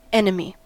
Ääntäminen
US : IPA : [ˈɛn.ə.mi]